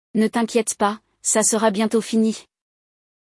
No episódio de hoje, acompanhamos uma conversa entre três pessoas durante um ensaio.